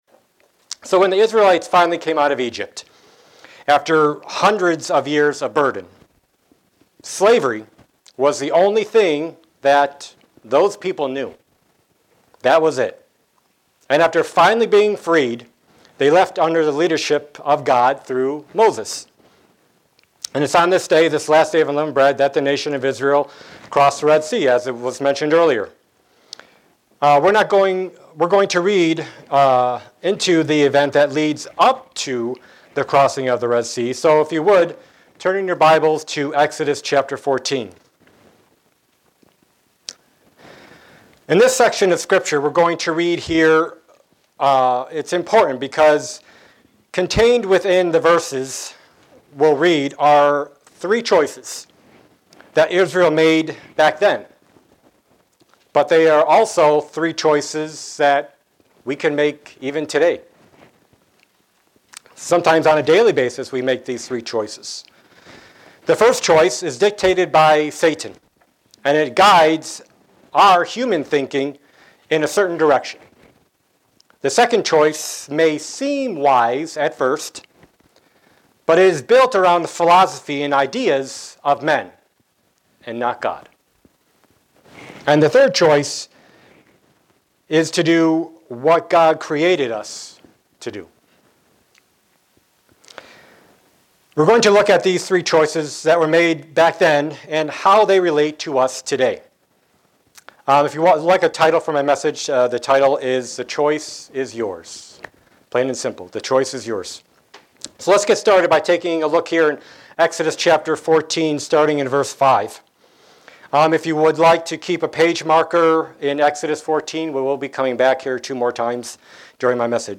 Sermons
Given in Buffalo, NY